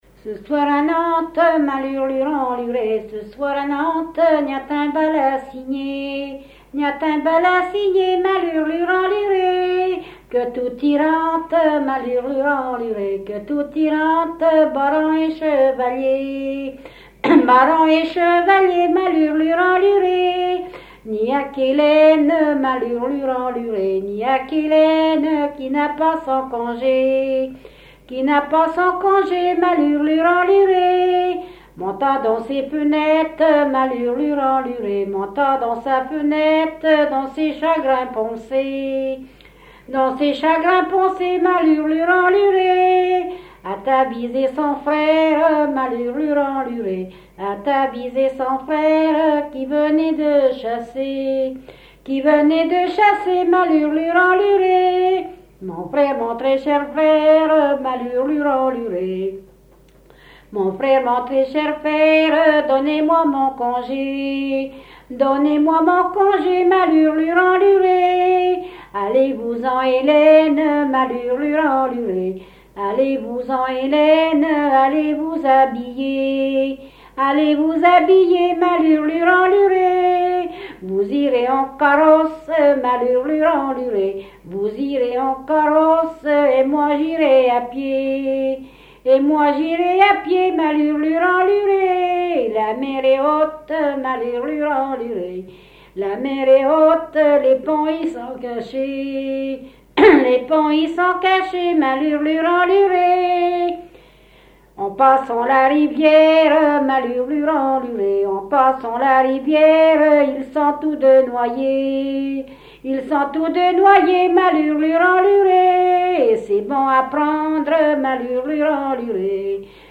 Localisation Barbâtre (Plus d'informations sur Wikipedia)
Fonction d'après l'analyste danse : ronde ;
Genre laisse
Catégorie Pièce musicale inédite